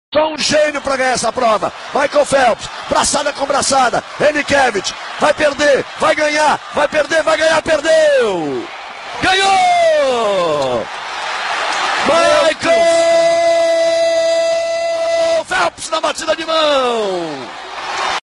Narração Galvão Bueno "Ganhou, Perdeu, Ganhou!"
Áudio com narração de Galvão Bueno em vitória do nadador Michael Phelps. Galvão se atrapalha todo se Phelps ganhou ou perdeu.